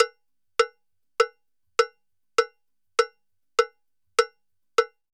Campana_Salsa 100_1.wav